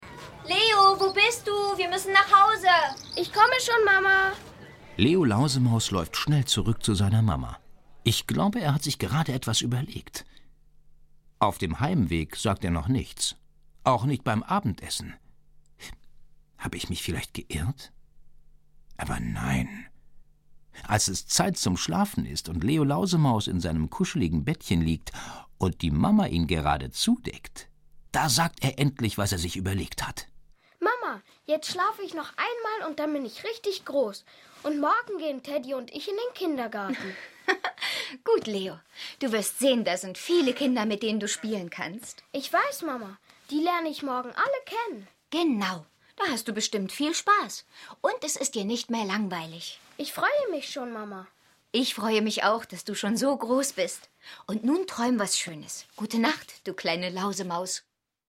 Ravensburger Leo Lausemaus - Folge 2 ✔ tiptoi® Hörbuch ab 3 Jahren ✔ Jetzt online herunterladen!